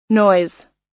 noise__us_1.mp3